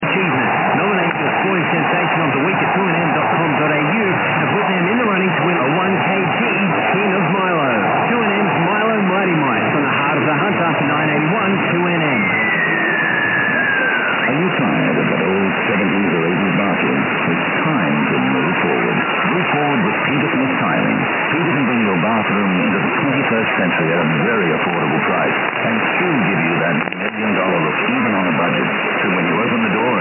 5/29　5月3回目のバイクチョイペに出動。
本日は18時少し過ぎに現地に到着し、もうすっかり慣れたΔFlagと機材を素早く設置。